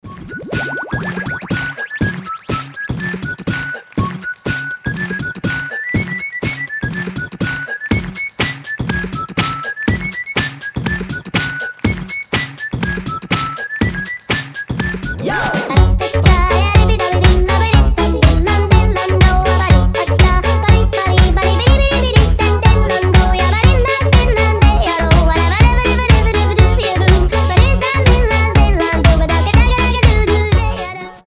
позитивные
свист
веселые
Euro House
битбокс
europop